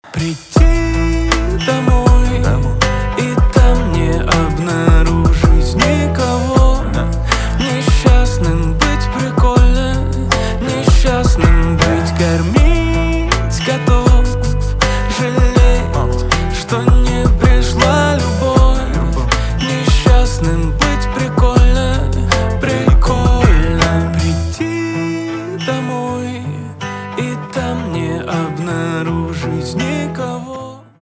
русский рэп , пианино
грустные , печальные